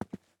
Player Character SFX / Footsteps